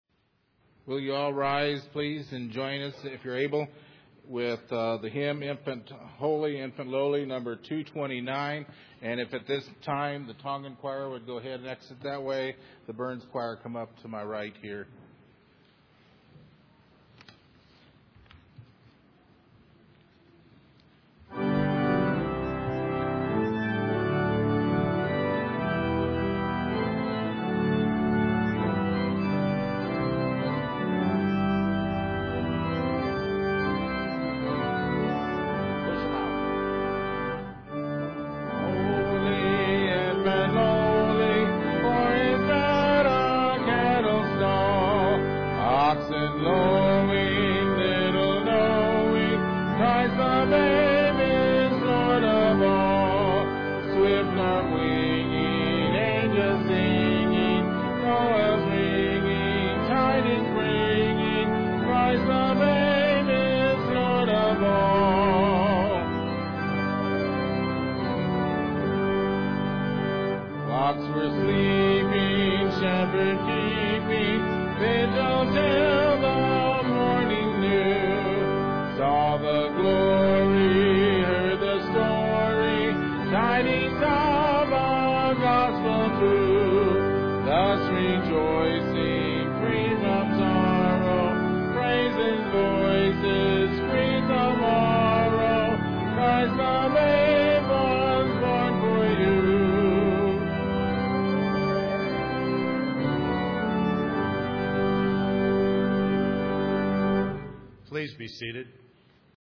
Christmas Eve Music Program & Service 2010
Hymn: "